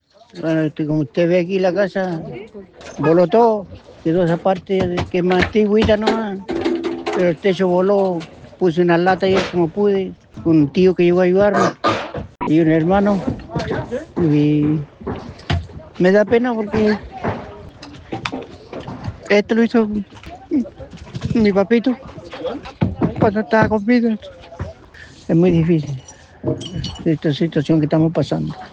Es muy difícil esta situación que estamos pasando”, relató visiblemente afectado.